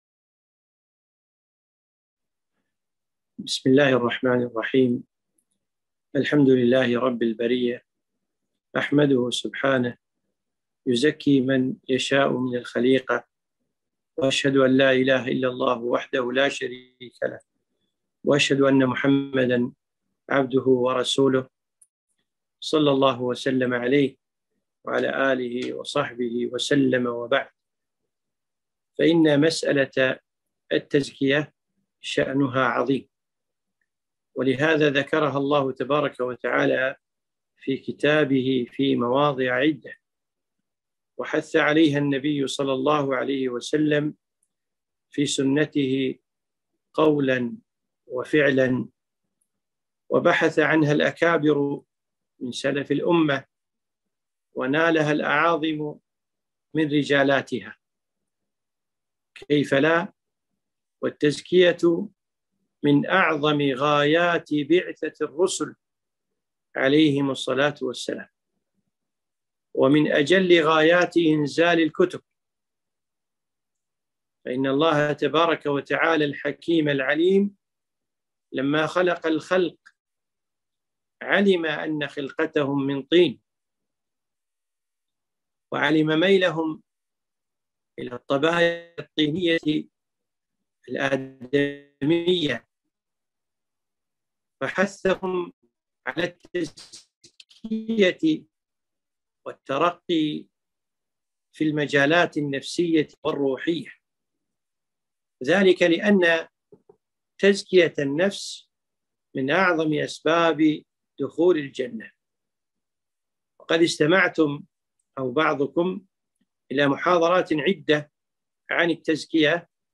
محاضرة - خوارم التزكية